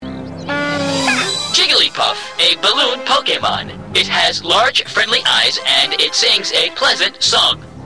- The Pokedex description of Jigglypuff (.wav)